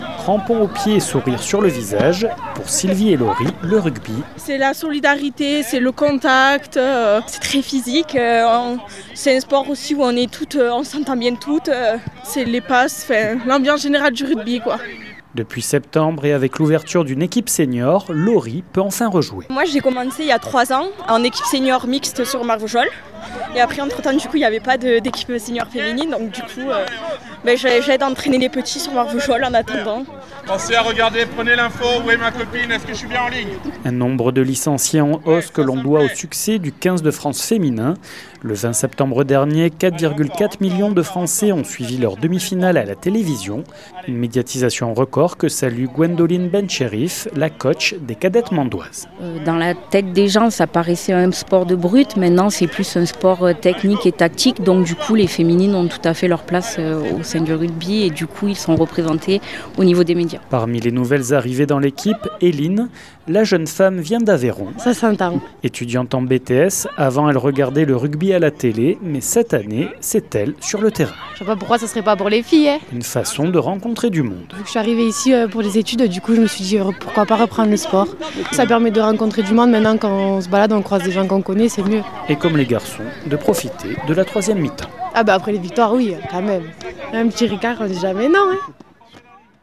Cette année par exemple, le Racing club Mende Lozère a enfin pu relancer une équipe Sénior, en plus de la section Cadettes en rugby à X. 48FM s’est rendu à l’un des entraînements.
Reportage